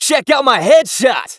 hotshot_kill_01.wav